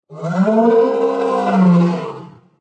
dinosaurroar.ogg